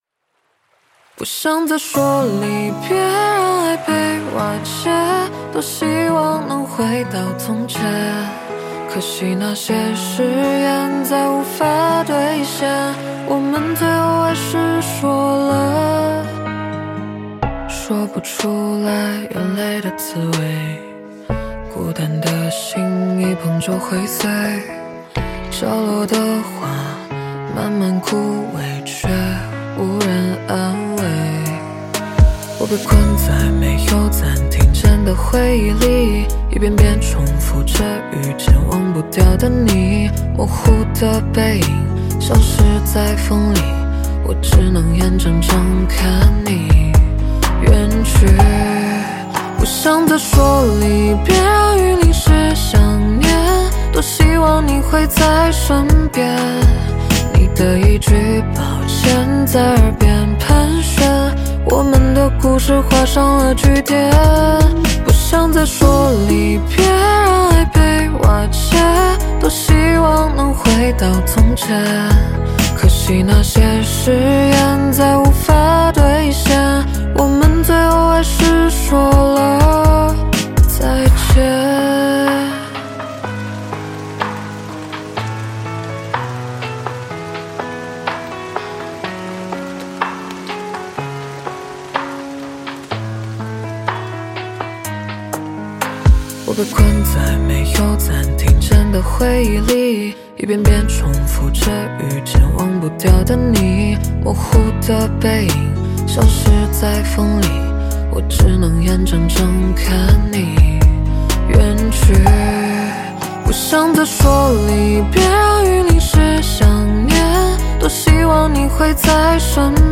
小伤悲的歌曲